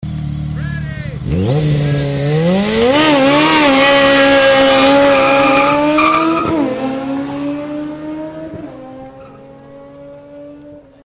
Power of Driving vs Driving Power, Beschleunigung Fahrzeuge (vor allem Motorrad K1200 R)